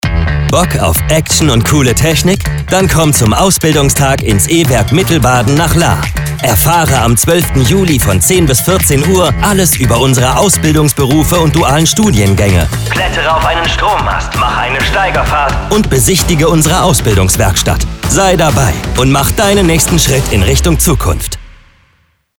Gerade eben durften wir diesen Radiospot für die E-Werk Mittelbaden Recruiting Kampagne produzieren.
Hörbeispiel – Recruiting Werbespot: